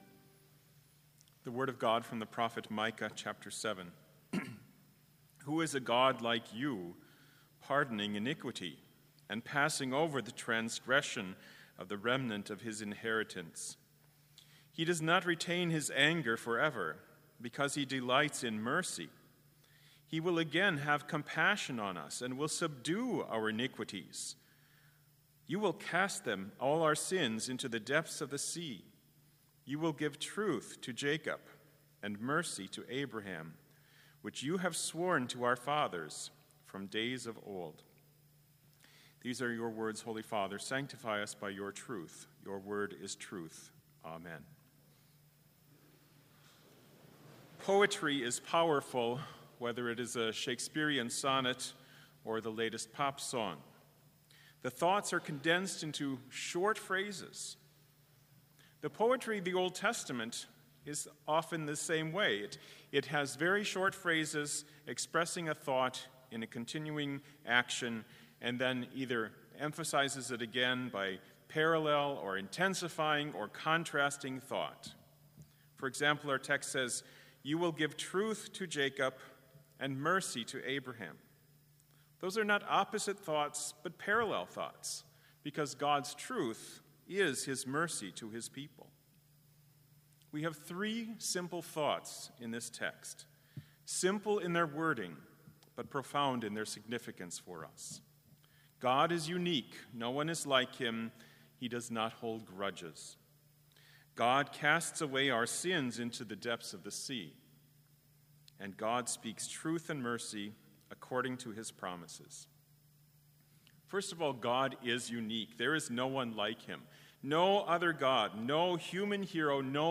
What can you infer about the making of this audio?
This Chapel Service was held in Trinity Chapel at Bethany Lutheran College on Tuesday, October 8, 2019, at 10 a.m. Page and hymn numbers are from the Evangelical Lutheran Hymnary.